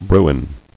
[bru·in]